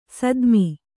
♪ sadmi